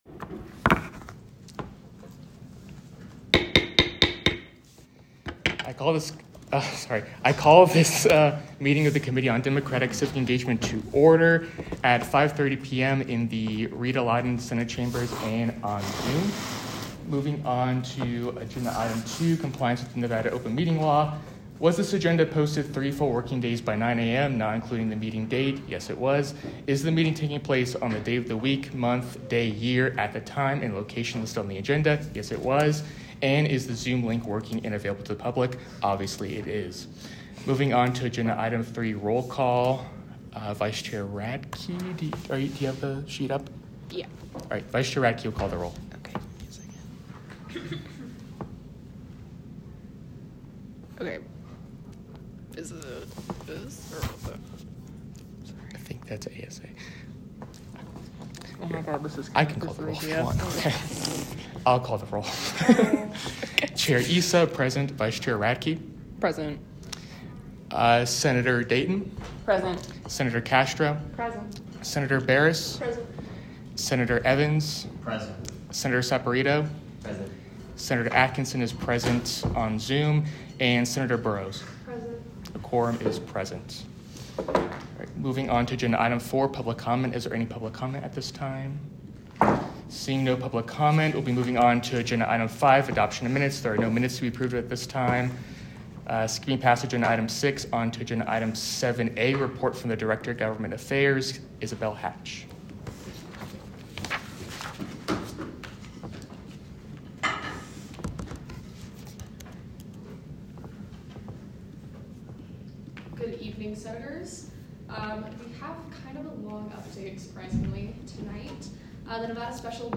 Meeting Type : Democratic Civic Engagement Committee
Location : Rita Laden Senate Chambers